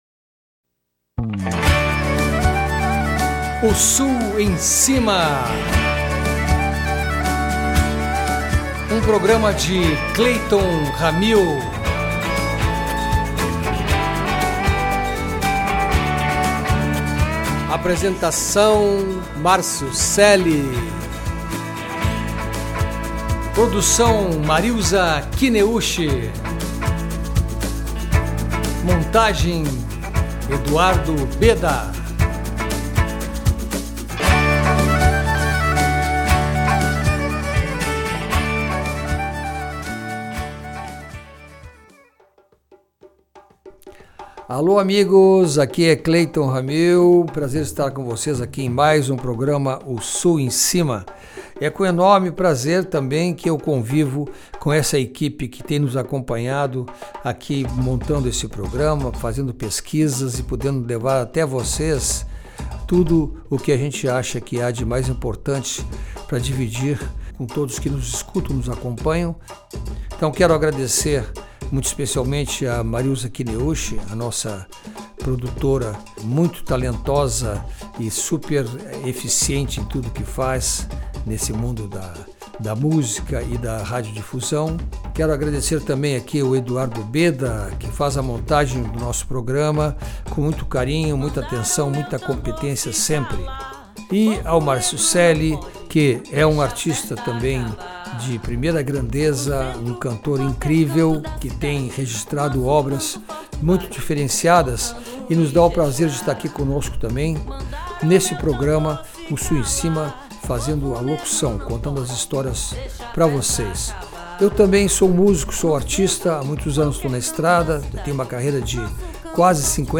Ritmos como forró, bumba meu boi, jongo, coco, ijexá e samba são expressos por meio da percussão, vozes e cordas.